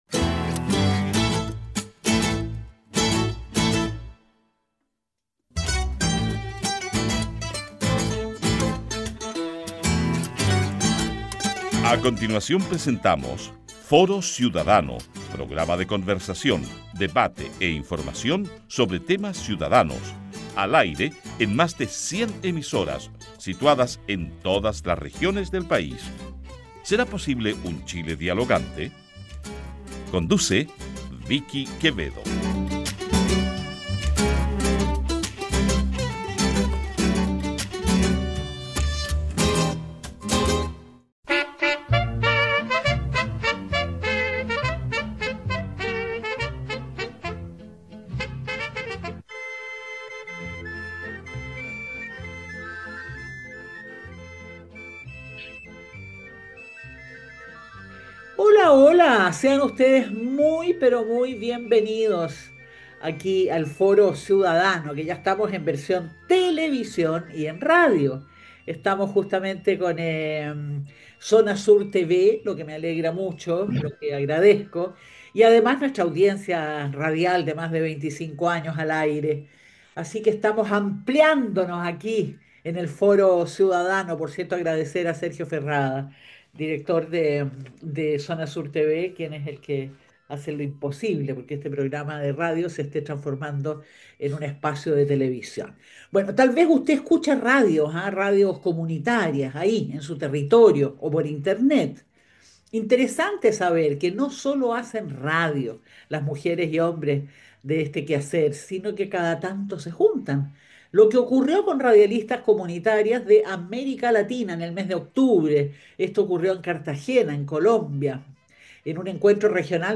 Interesante saber que cada tanto se juntan, lo que ocurrió con Radialistas comunitarios de América Latina en el mes de Octubre en Cartagena-Colombia. Un Encuentro Regional de Representantes y Referentes Nacionales de AMARC (Asociación Mundial de Radios Comunitarias), sede en AL. Asistieron radialistas de Chile, Colombia, Cuba, El Salvador, Ecuador, Guatemala, Honduras, México, Panamá y Uruguay . Conversamos con